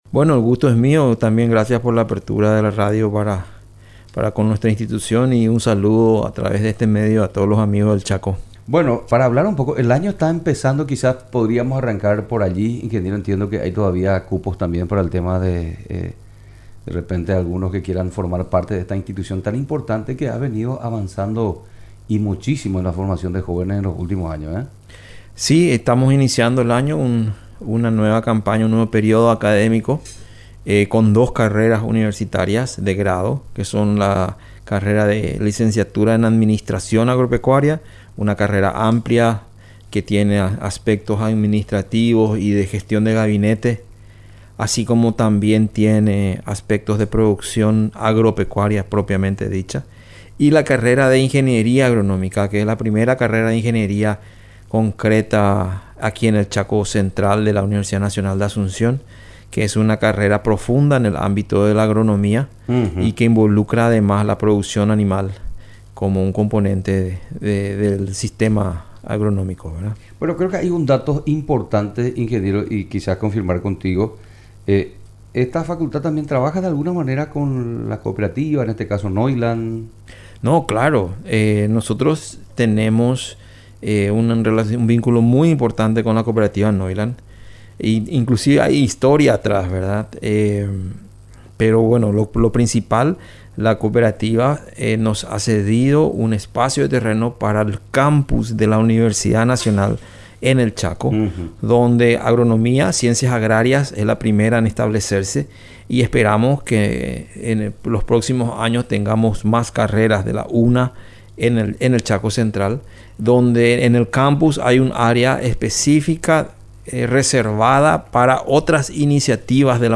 Entrevistas / Matinal 610